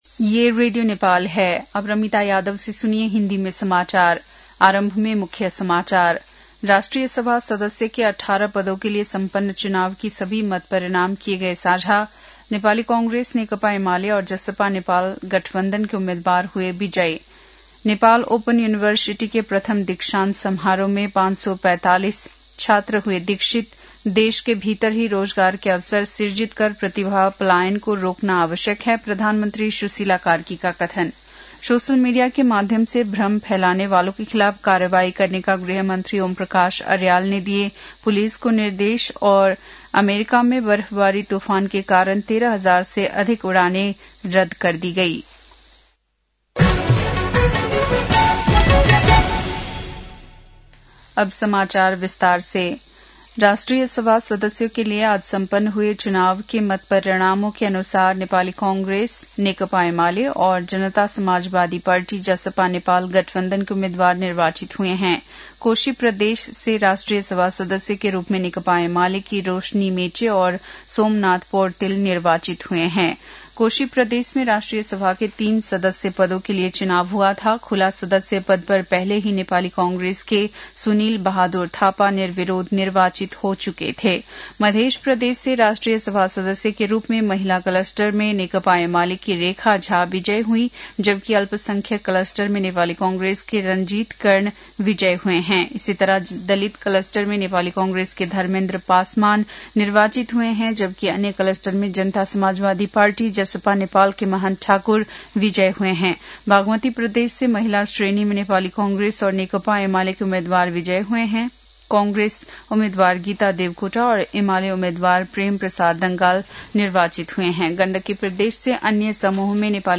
बेलुकी १० बजेको हिन्दी समाचार : ११ माघ , २०८२